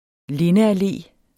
Udtale [ ˈlenəaˌleˀ ]